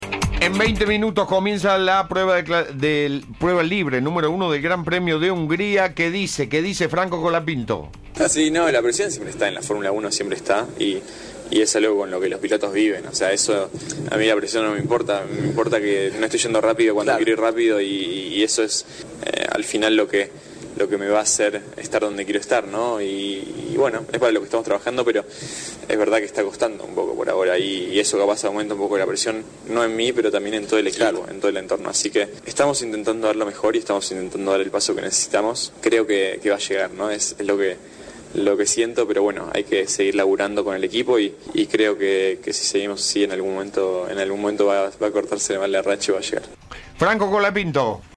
El piloto habló sobre sus desafíos para mejorar. Destacó la importancia del trabajo en equipo.